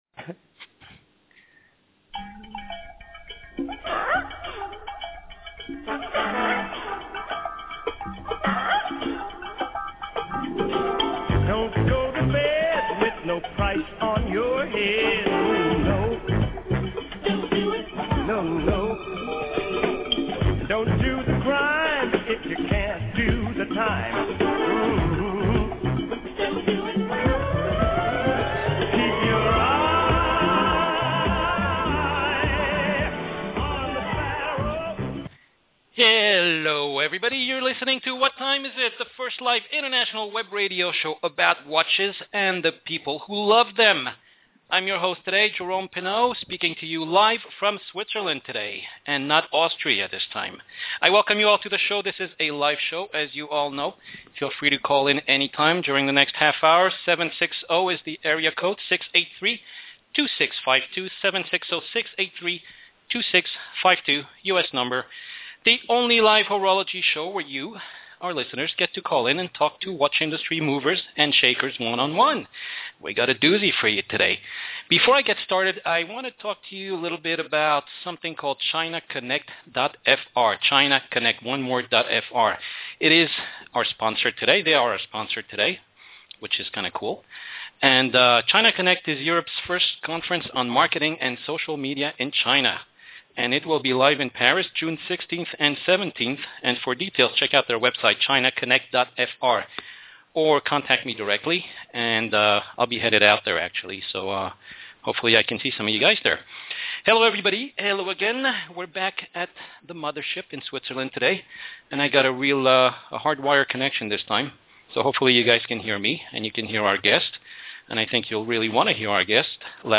Interviews
“What Time Is It?” is the first live international web radio show about watches and the people who love them!